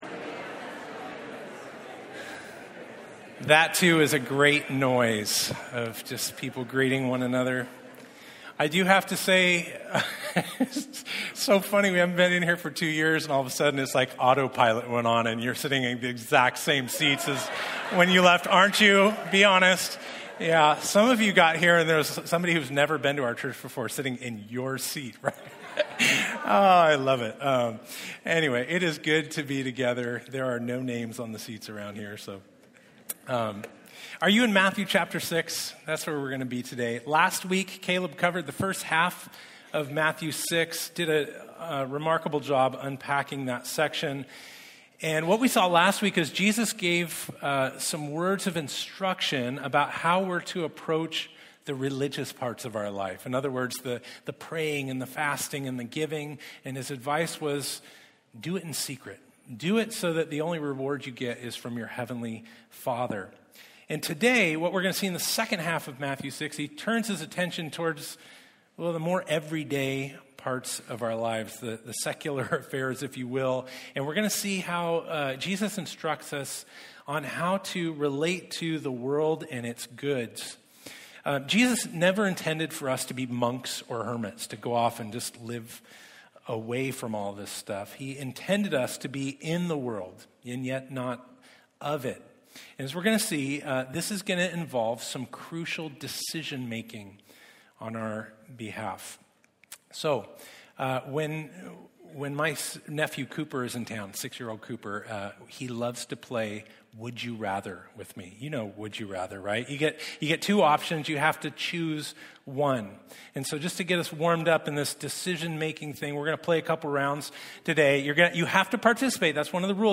Matthew Passage: Matthew 6:19-34 Service Type: Sunday Topics